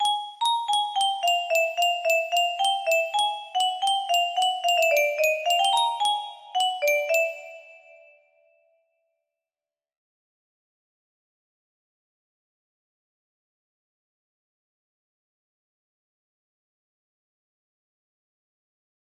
bad ending music box melody